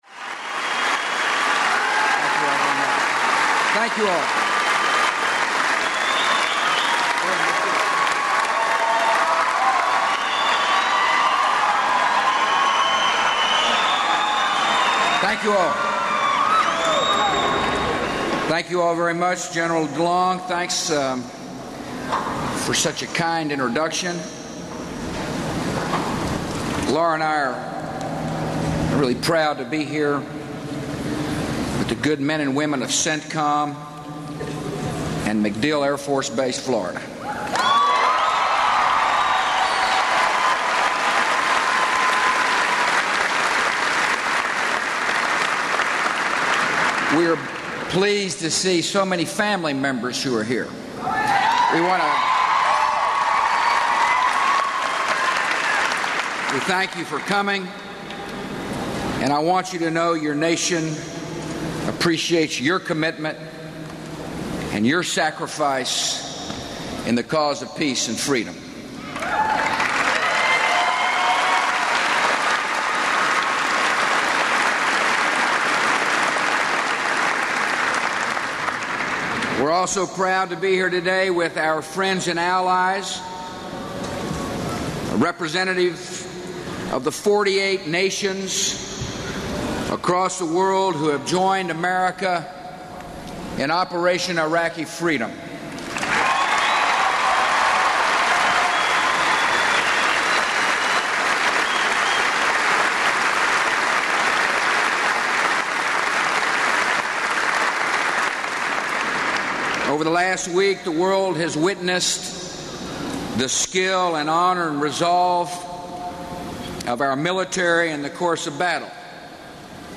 Broadcast on CNN, Mar. 26, 2003.